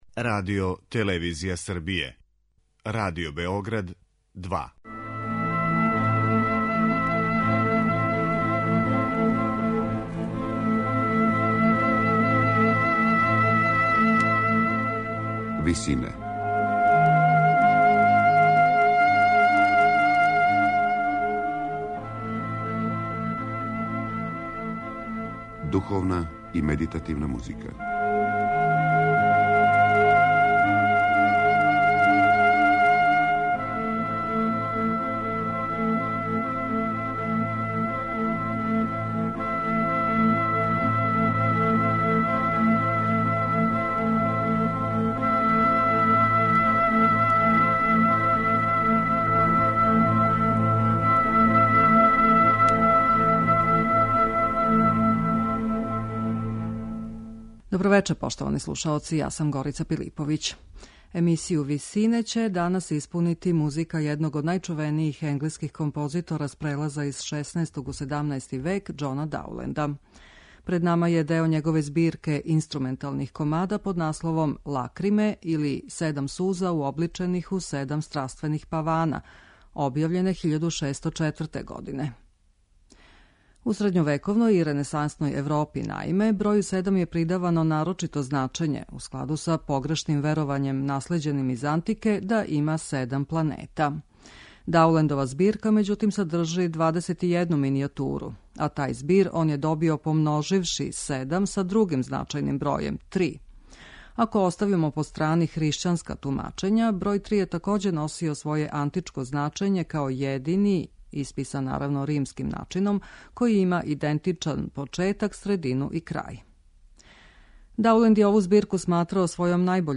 Биће то део његове збирке инструменталних комада под насловом Лакриме или седам суза уобличених у седам страствених павана.